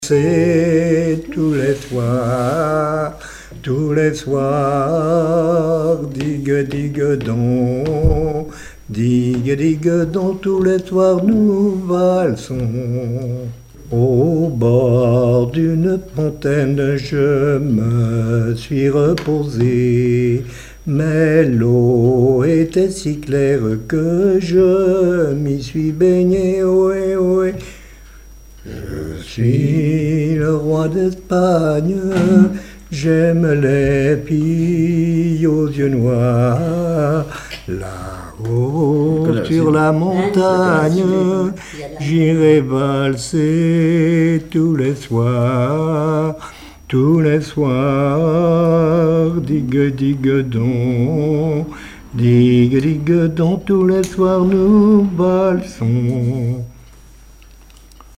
marche de cortège de noce
Enquête Arexcpo en Vendée-Association Joyeux Vendéens
Pièce musicale inédite